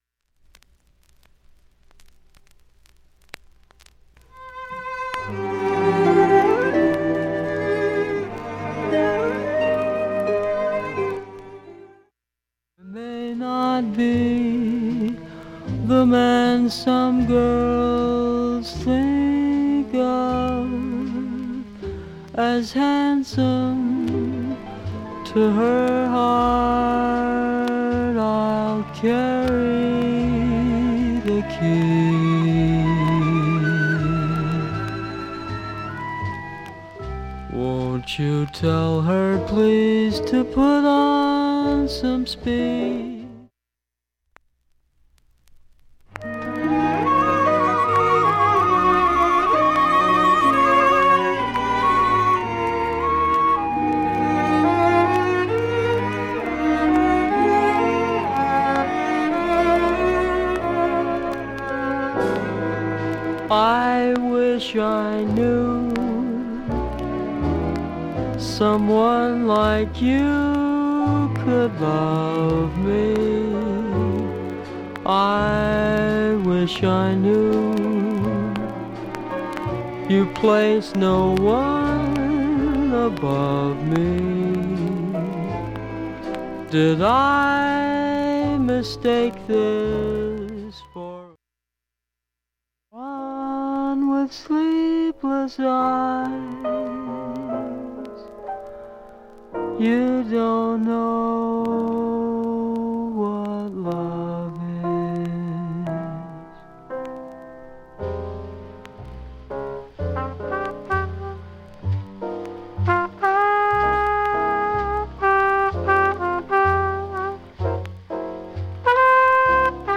影響はほとんど無く音質良好全曲試聴済み。
周回プツ出ますがかすかなレベルで